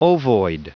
Prononciation du mot ovoid en anglais (fichier audio)
Prononciation du mot : ovoid